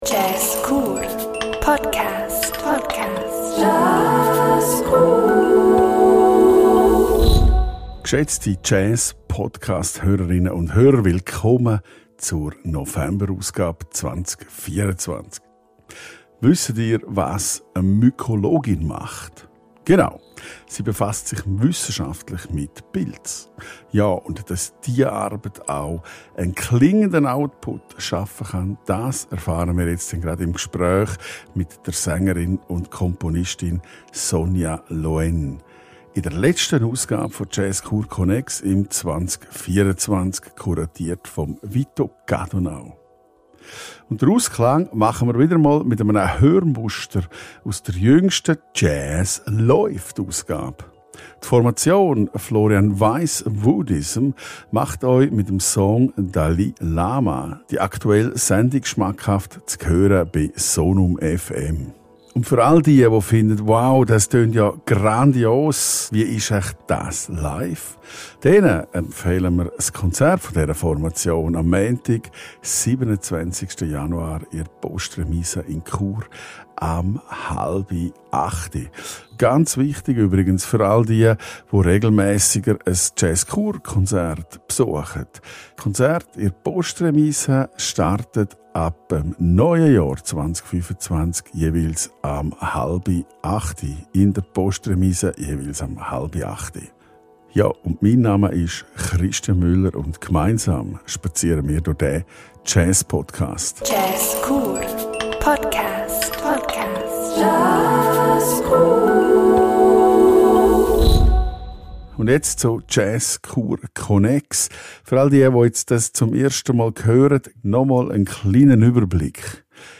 Sängerin und Komponistin